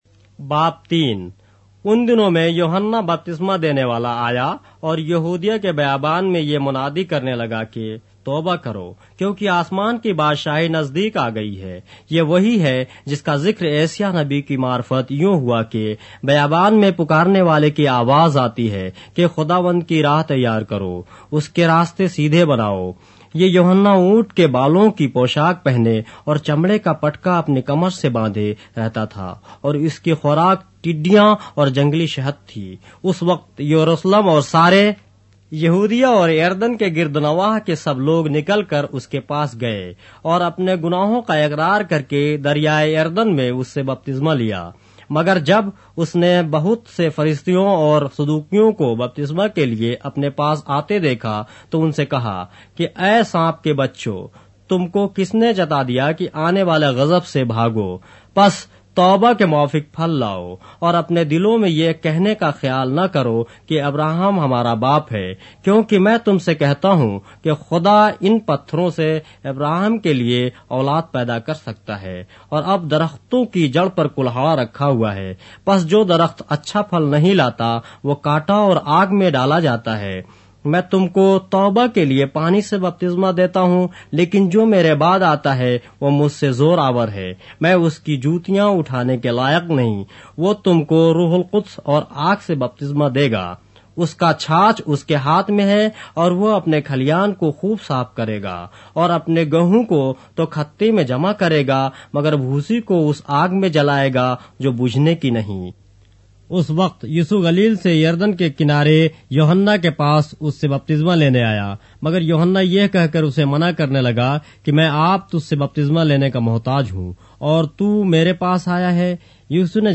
اردو بائبل کے باب - آڈیو روایت کے ساتھ - Matthew, chapter 3 of the Holy Bible in Urdu